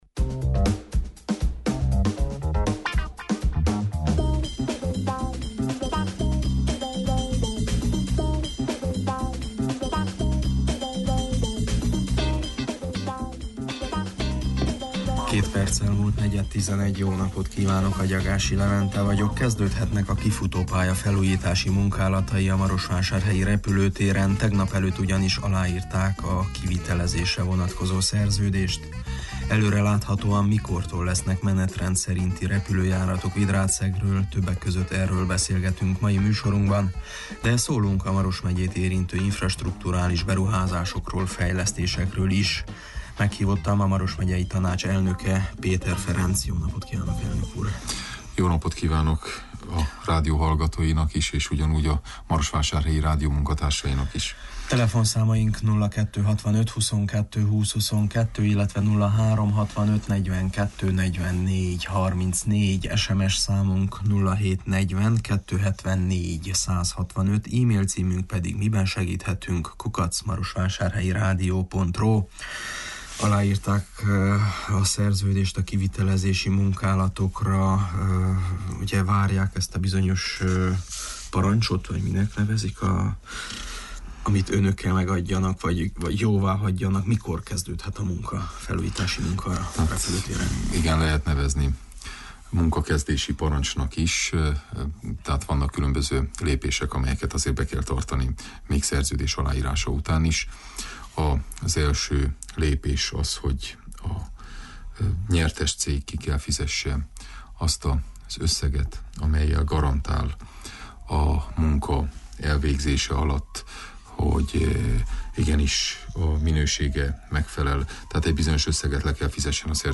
Miben segíthetünk? című műsorunkban nyilatkozott